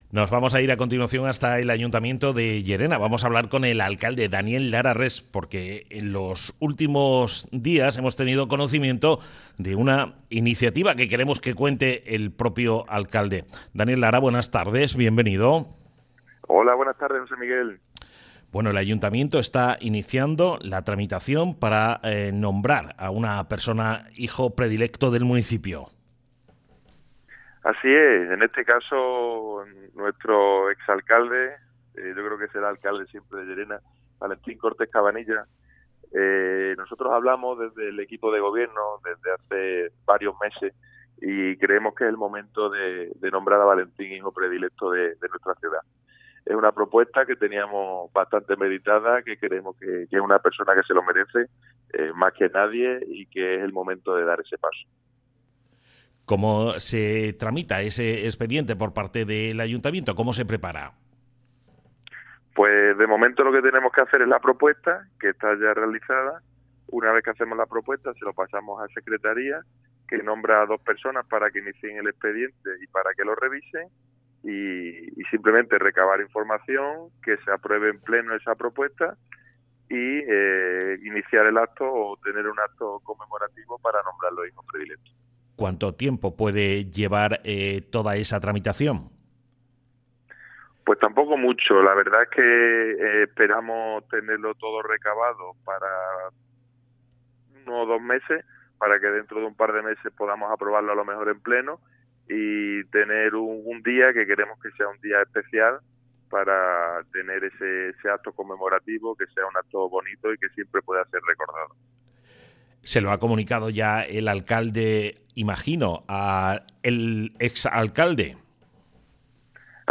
El alcalde de Llerena, Daniel Lara Rex, ha contado en Onda Cero que ha iniciado el expediente para nombrar Hijo Predilecto de Llerena al ex-alcalde Valentín Cortés Cabanillas.